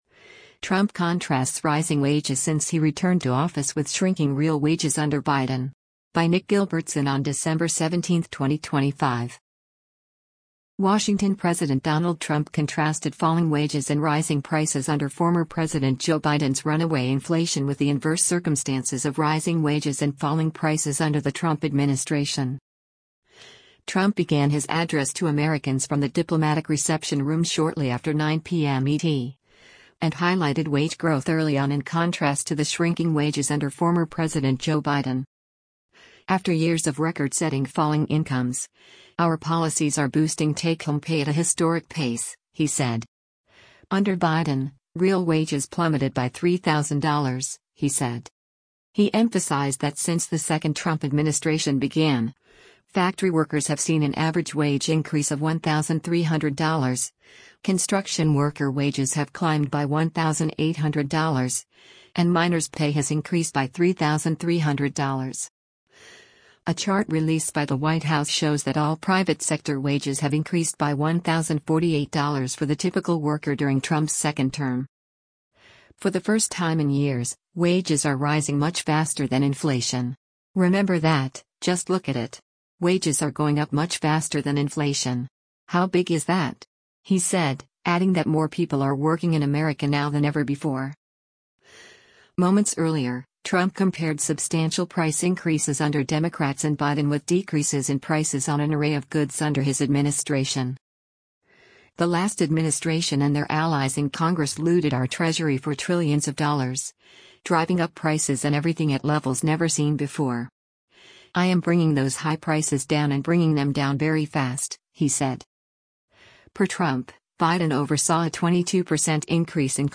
US President Donald Trump during a prime-time address to the nation in the Diplomatic Rece
Trump began his address to Americans from the Diplomatic Reception Room shortly after 9:00 p.m. ET, and highlighted wage growth early on in contrast to the shrinking wages under former President Joe Biden.